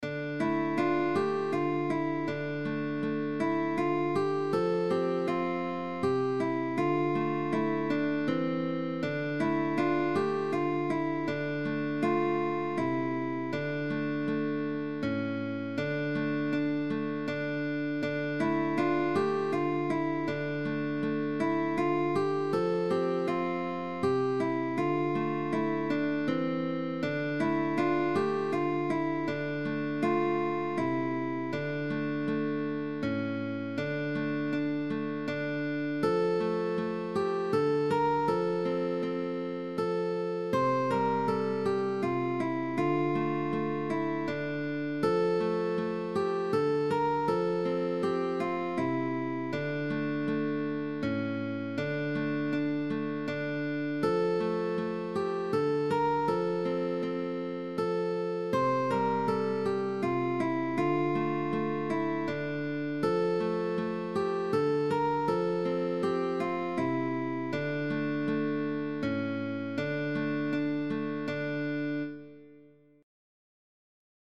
Tag: Early music